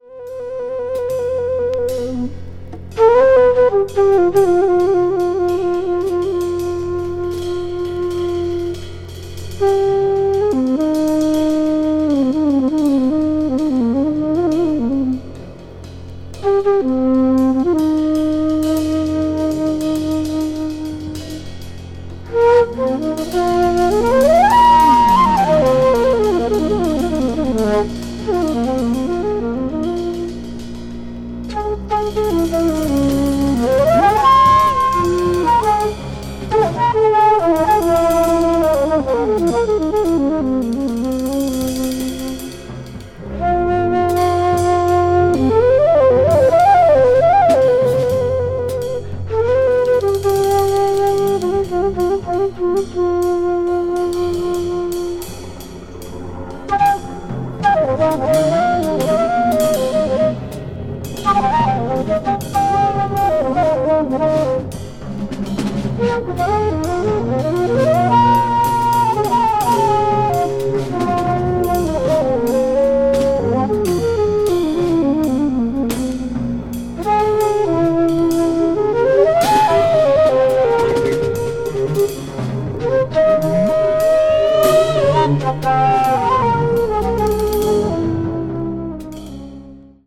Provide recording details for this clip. media : EX/EX(some slightly surface noises.)